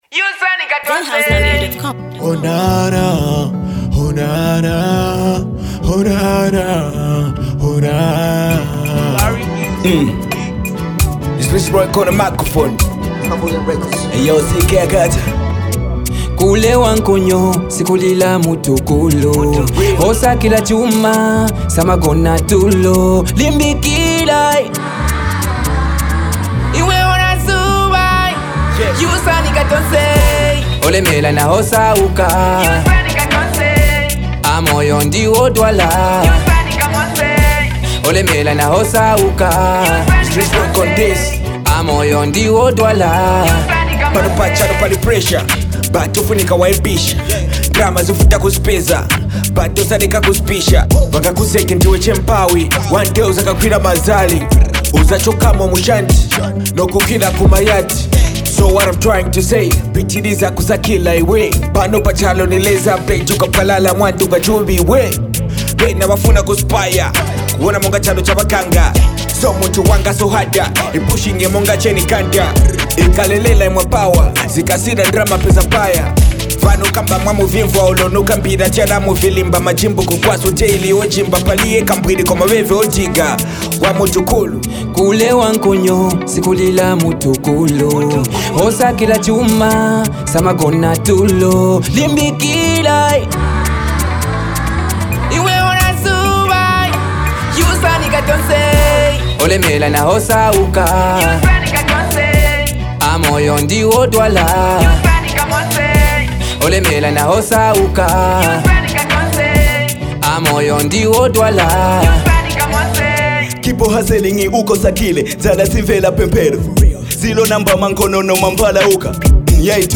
With compelling lyrics and dynamic beats